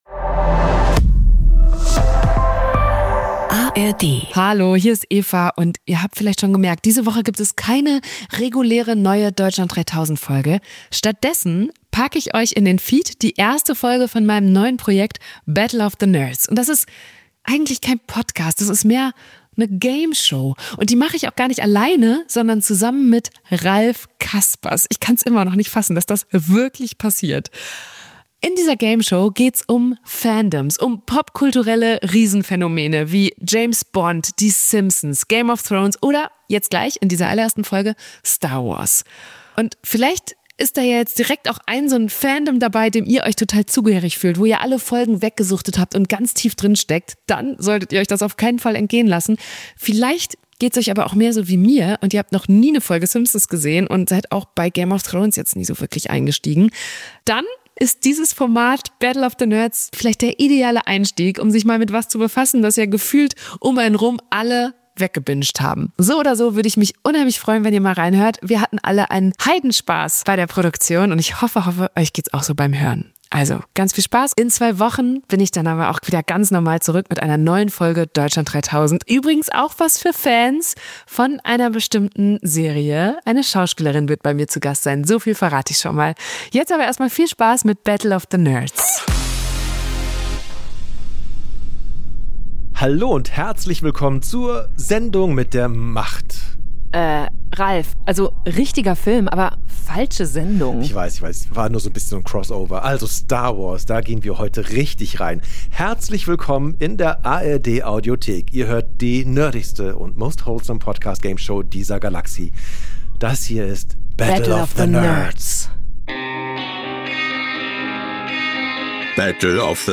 Und das ist eigentlich kein Podcast, das ist mehr eine Gameshow. Und die mache ich auch gar nicht alleine, sondern zusammen mit Ralph Caspers.